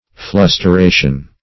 Search Result for " flusteration" : The Collaborative International Dictionary of English v.0.48: Flusteration \Flus`ter*a"tion\, n. The act of flustering, or the state of being flustered; fluster.